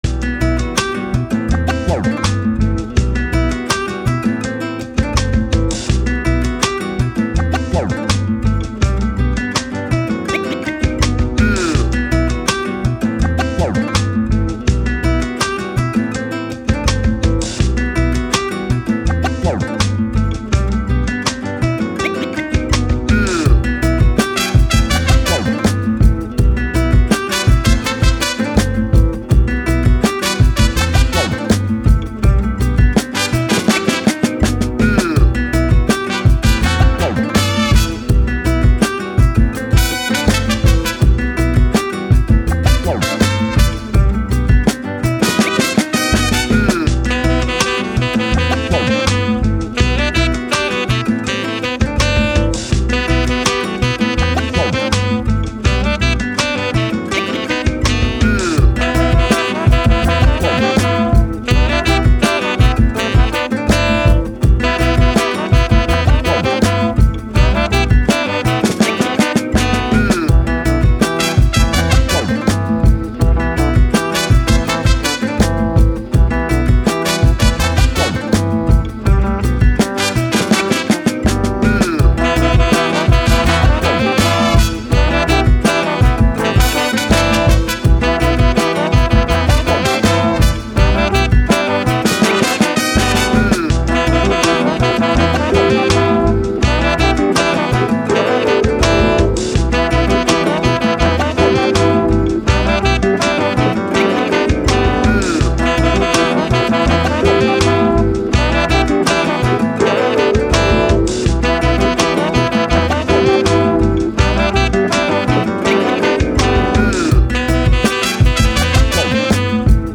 Hip Hop, Latin, Jazz, Upbeat, Moody